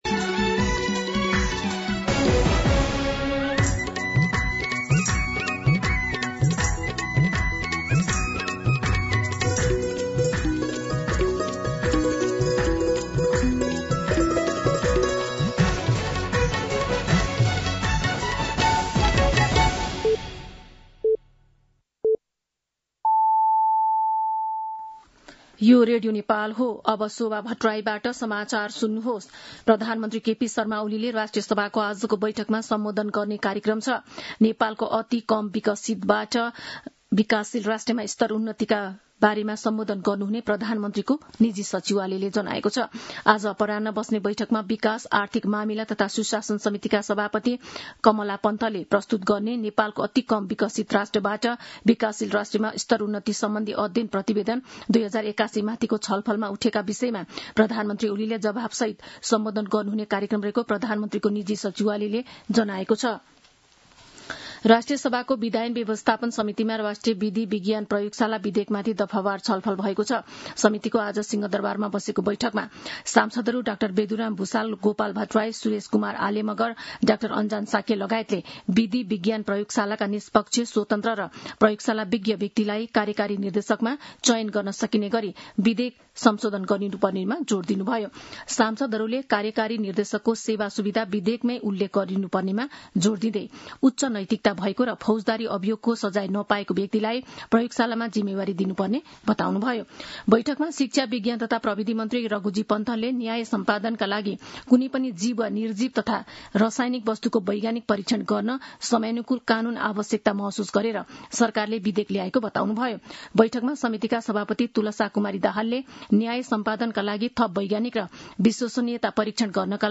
An online outlet of Nepal's national radio broadcaster
दिउँसो १ बजेको नेपाली समाचार : १४ साउन , २०८२